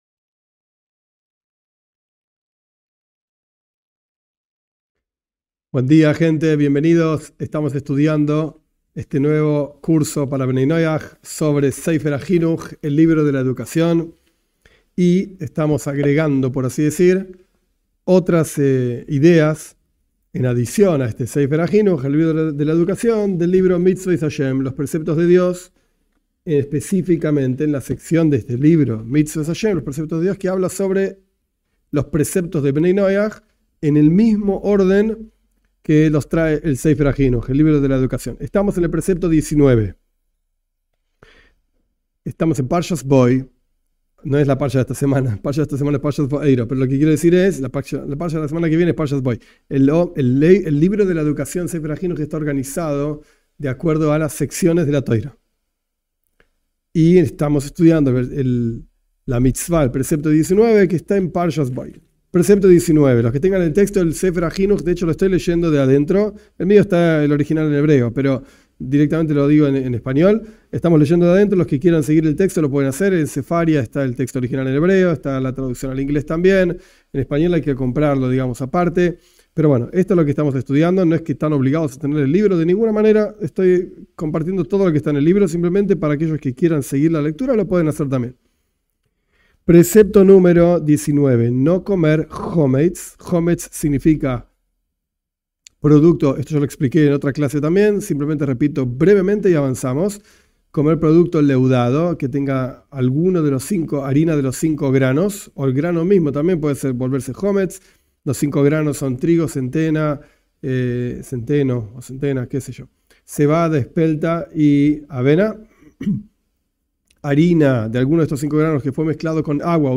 En este curso estudiaremos los preceptos del judaísmo en forma breve, basándose en el libro de la educación (Sefer HaJinuj) y aplicándolos a Bnei Noaj de acuerdo a los escritos de Rabí Ionatan Steiff (1877-1958). En esta clase estudiamos los preceptos relacionados a Pesaj.